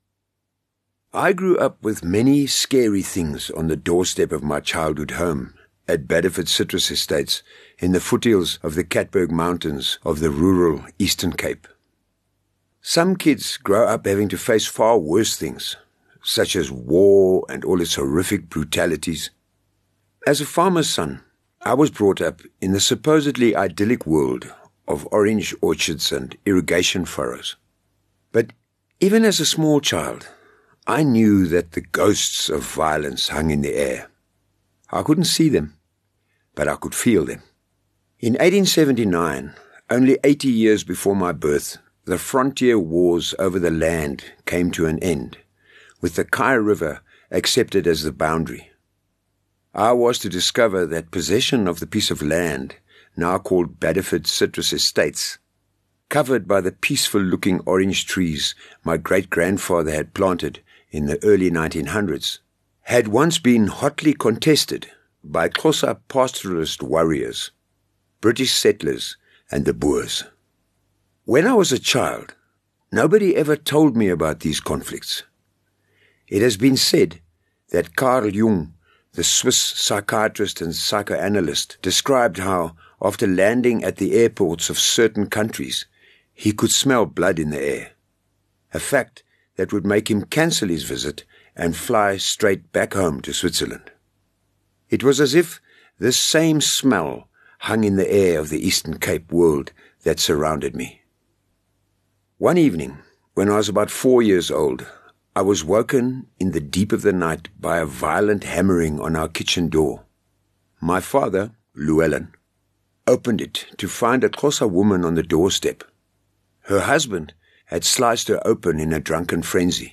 Audiobook Samples from Solid Gold